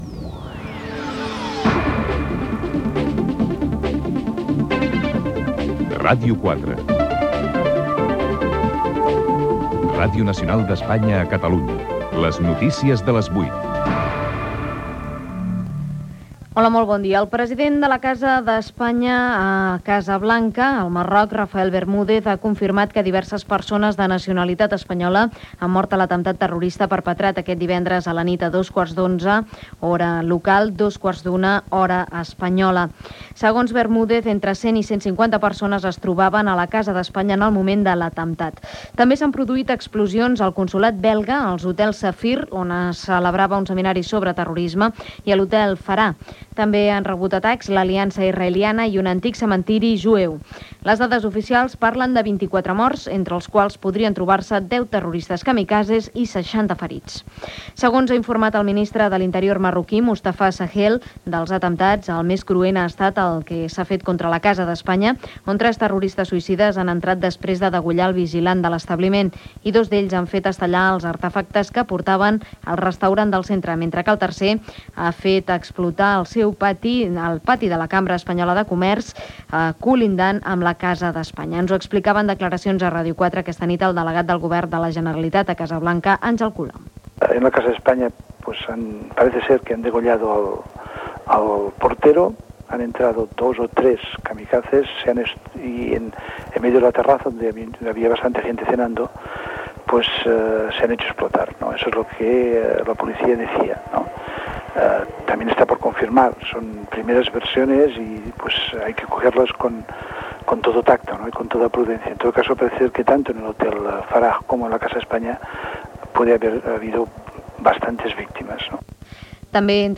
Atemptat a la casa d'Espanya del Marroc, atemptat en un caixer de Barcelona, emissió del vídeo d'ETA per Euskal Telebista. Indicatiu de l'emissora.
Informatiu
FM